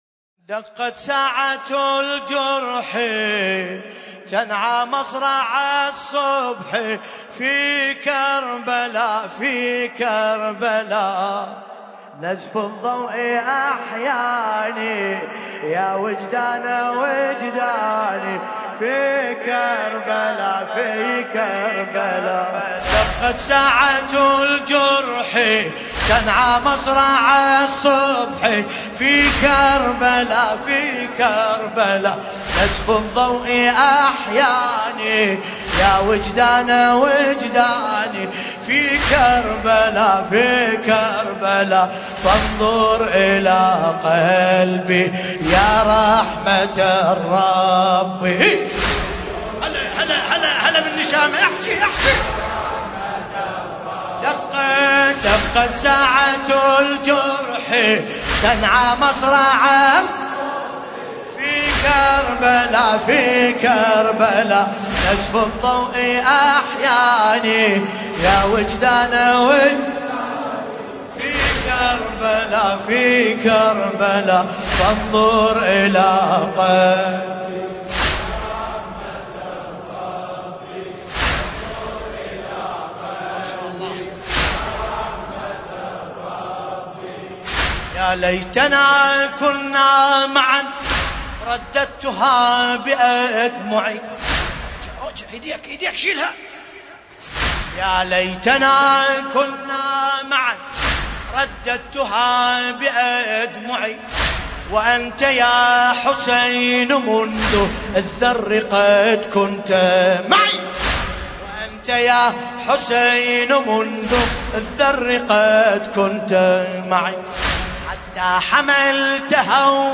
ملف صوتی دقت ساعة الجرح بصوت باسم الكربلائي
القصيدة : دقت ساعة الجرحالشاعر : علي عسيلي العاملي المناسبة : ليلة ١ محرم ١٤٤١ المكان : ديوان الكفيل_لندن